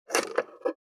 482,野菜切る,咀嚼音,ナイフ,調理音,まな板の上,料理,
効果音